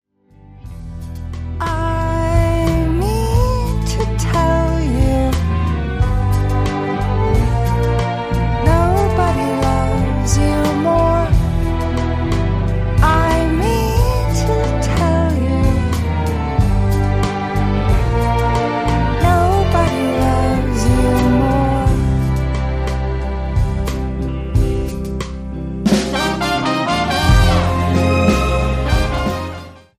Indie / Alternativa